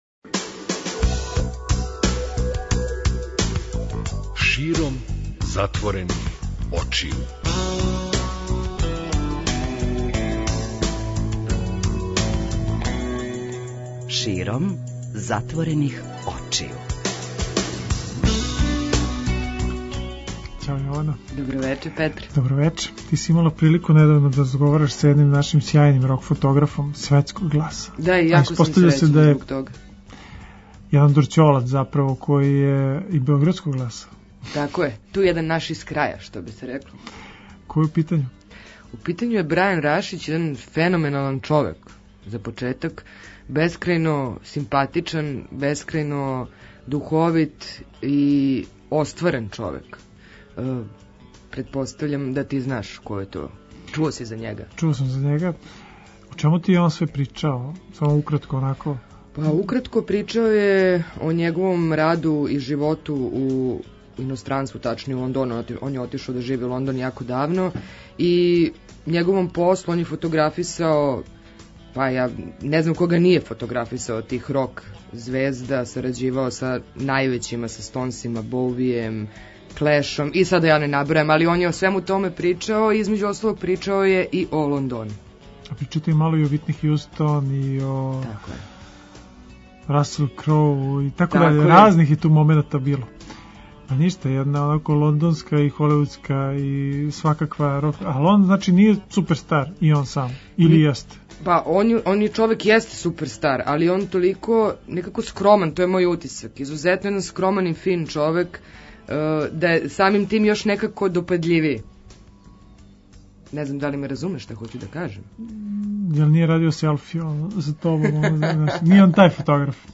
Била је то ексклузивна прилика да разговарамо са човеком који истински воли радио и музику, и који је рођени Дорћолац.